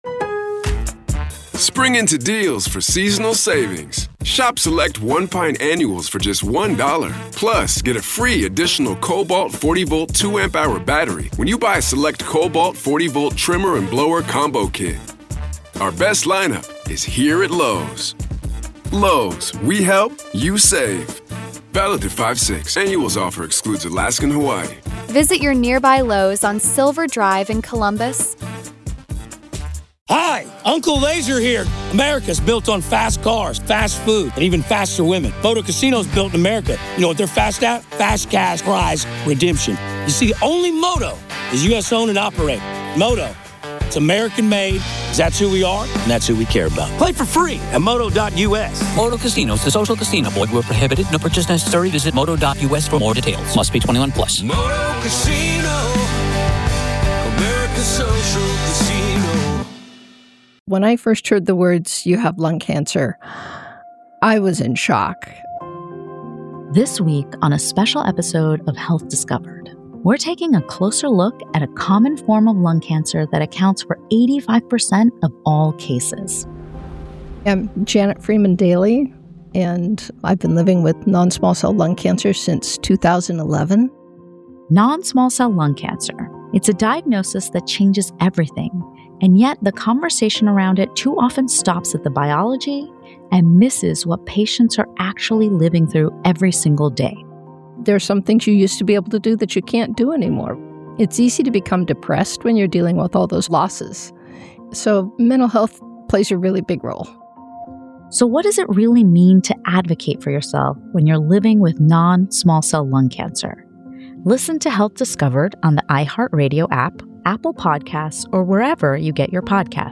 In Part 2 of a three-part interview series